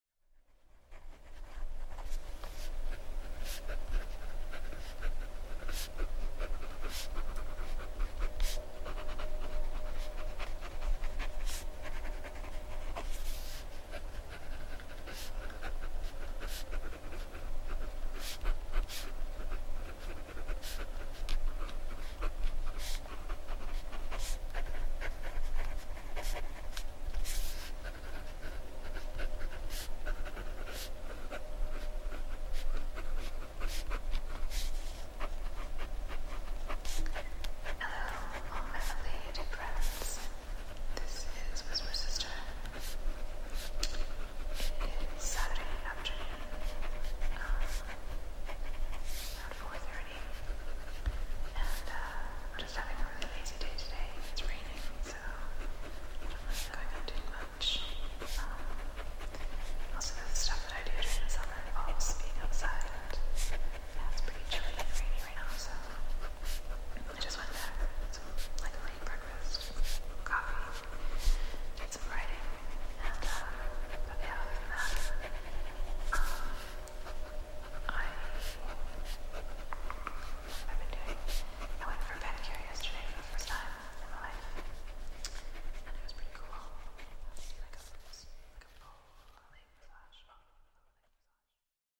私の抱く「アルベルチーヌ」のイメージに非常に迫る音でありながら、それを更に超越した捉えようのない距離感も同時に備えている。
時に私に慰めを与え、時に挑発的に裏切る「毒」を含んだ緊迫感溢れる音の嵐が始まりも終わりもなく脳を直撃し、身体は熱を帯び浮遊し始める。